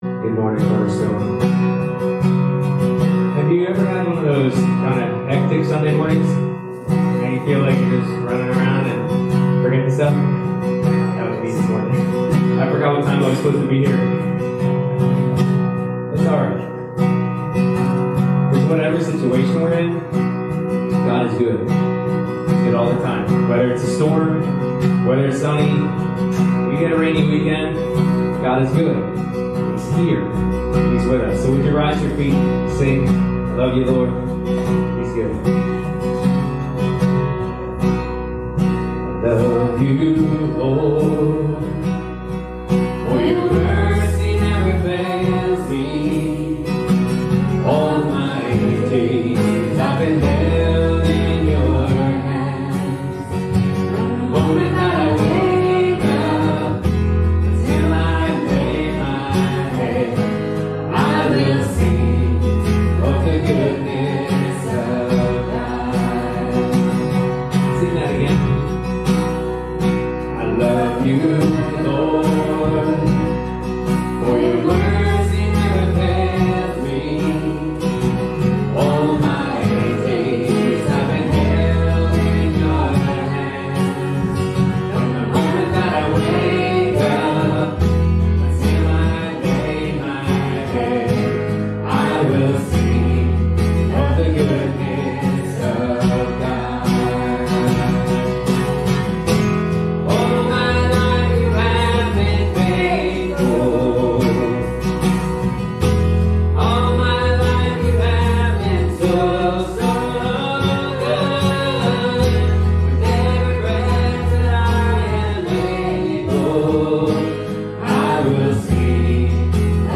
Passage: 1 Kings 21 Service Type: Sunday Morning Sermon